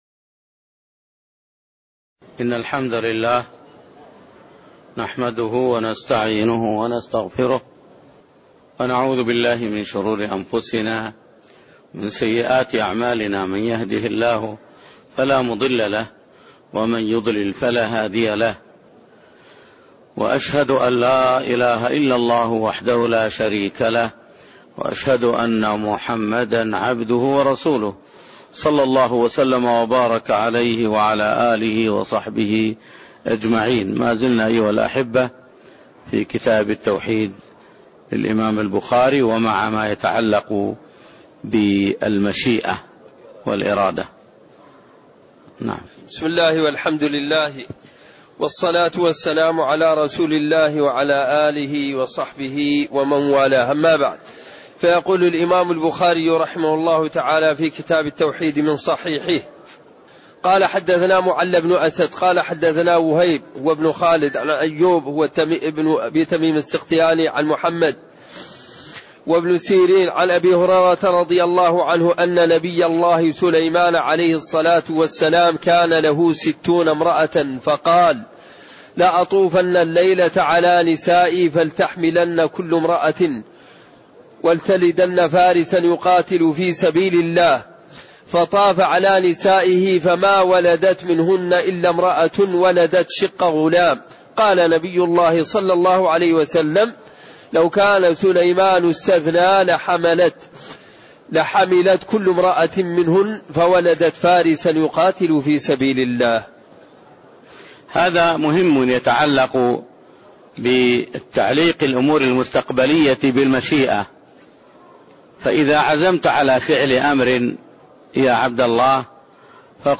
تاريخ النشر ١٨ صفر ١٤٣٥ هـ المكان: المسجد النبوي الشيخ